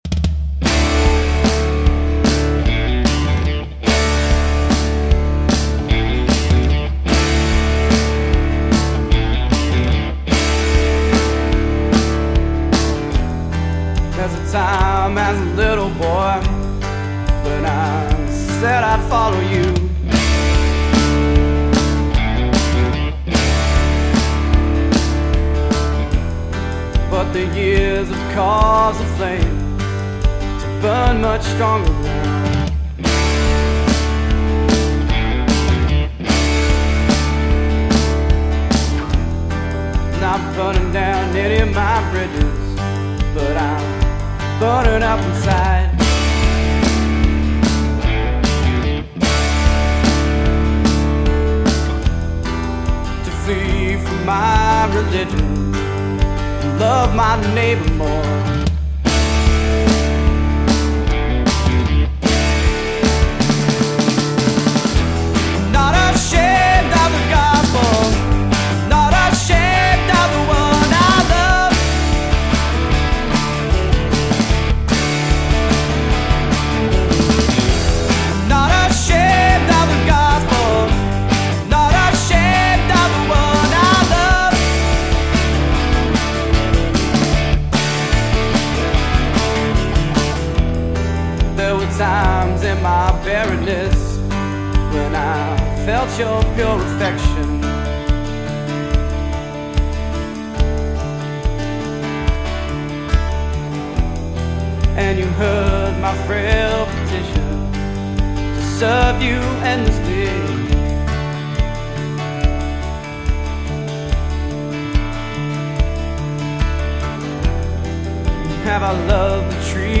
Acoustic Guitar
Background Vocals
Electric Guitar
Lead Vocals, Drums
Bass Guitar